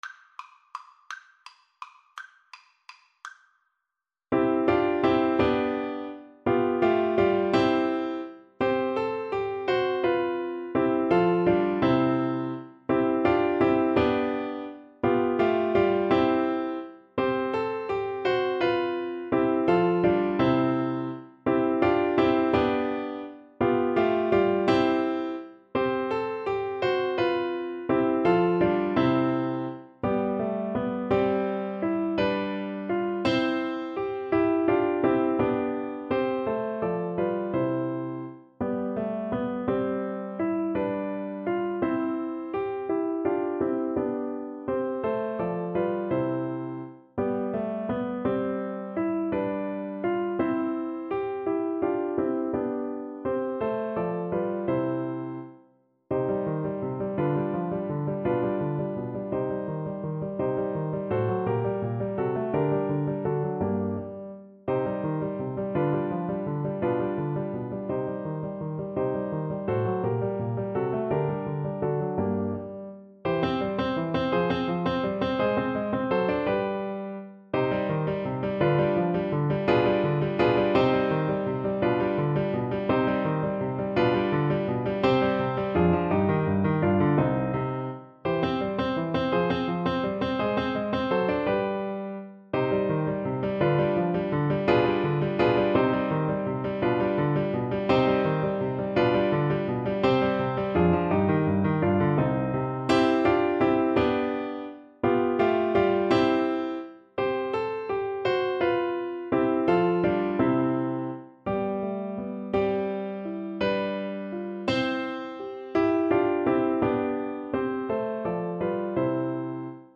Lustig (Happy) .=56
3/8 (View more 3/8 Music)
Classical (View more Classical Violin Music)